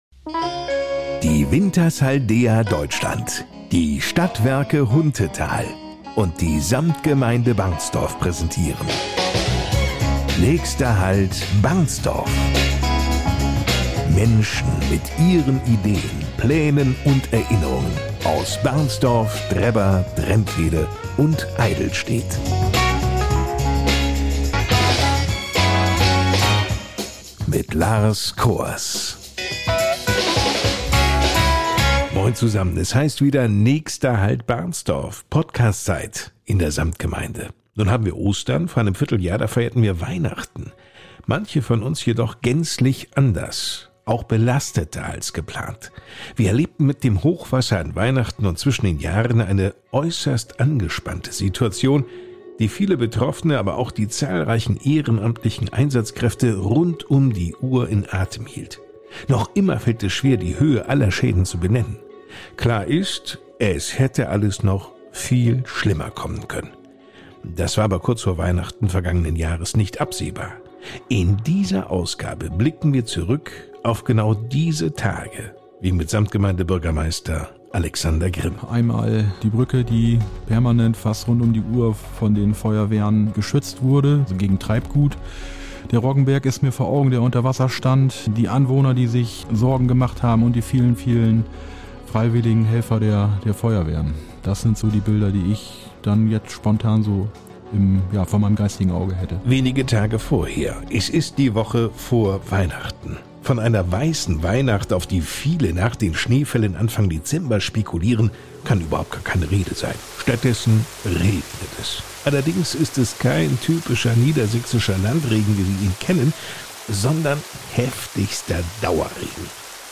In jeder Ausgabe unserer Podcastreihe NÄCHSTER HALT BARNSTORF widmen wir uns einem Thema aus der Samtgemeinde Barnstorf und sprechen darüber mit Menschen aus Barnstorf, Drebber, Drentwede und Eydelstedt über ihre Ideen, Pläne oder auch Erinnerungen.